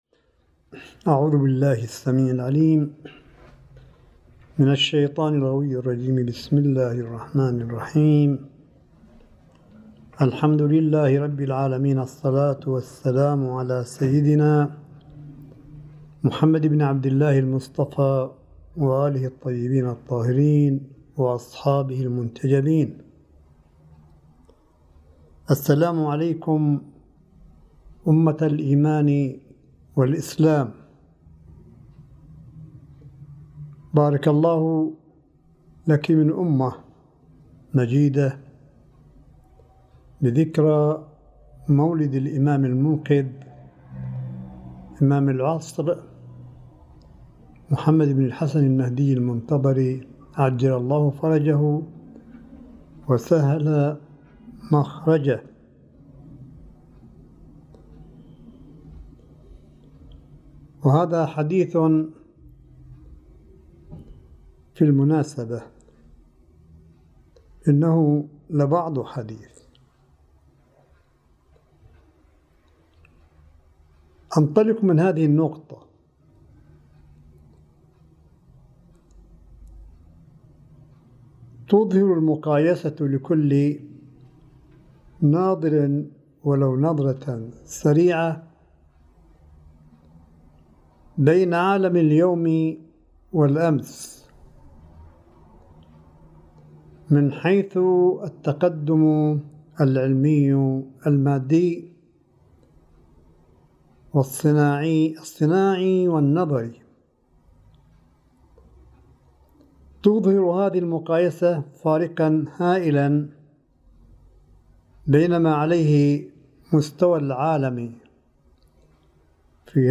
صوت : كلمة آية الله قاسم بمناسبة مولد الامام المهدي (عج) 1441 هـ